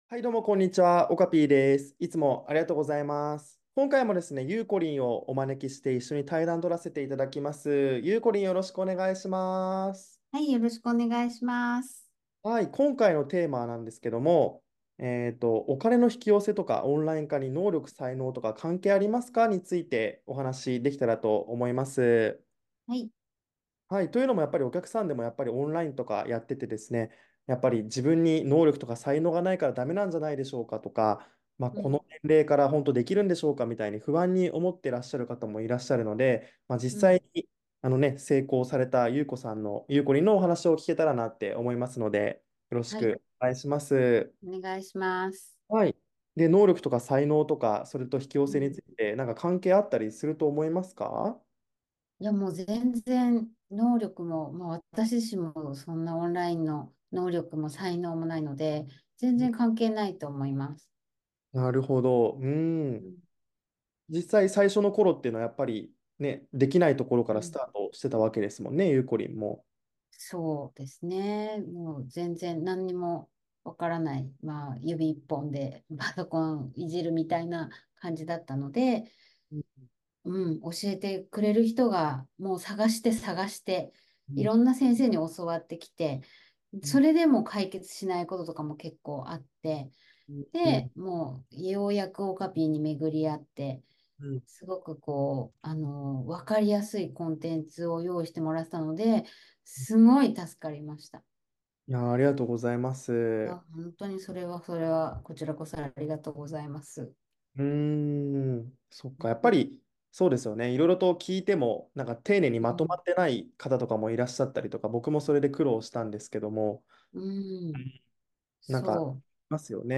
対談音声”6本”